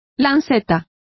Complete with pronunciation of the translation of lance.